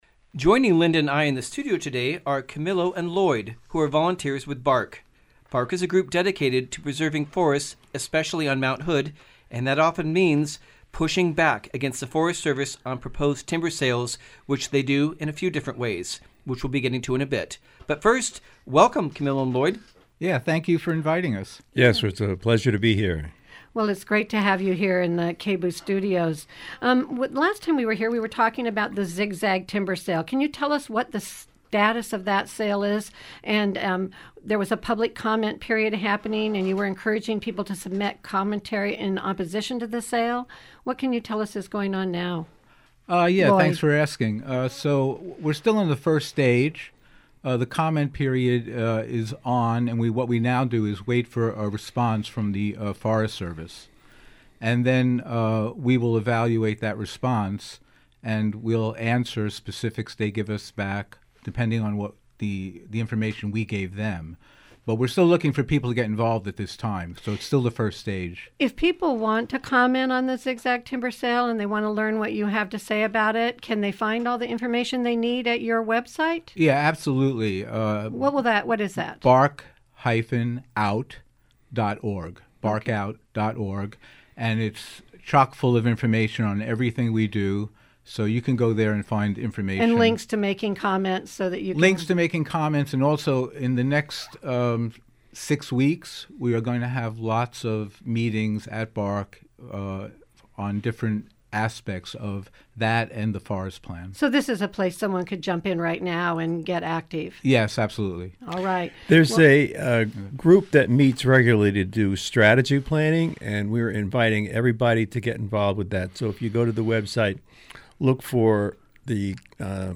bark_march_interview.mp3